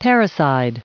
469_parricide.ogg